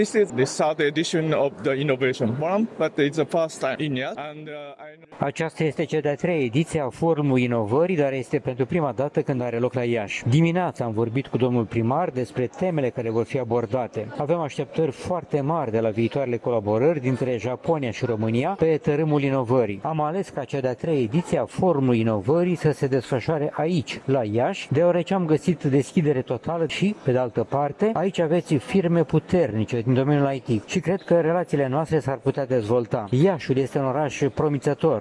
Ceremonia de deschidere a avut loc, astăzi, la Palatul Culturii, unde a fost prezent și Excelența Sa, ambasadorul Japoniei la București, Takashi Katae.